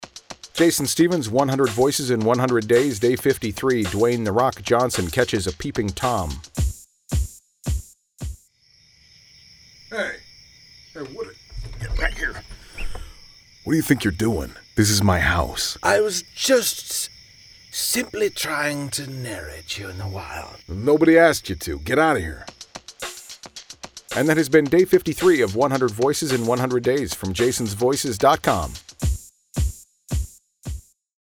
But, today, I’m trying out my Dwayne Johnson impression.
Tags: celebrity impersonations, Dwayne Johnson impression, The Rock voice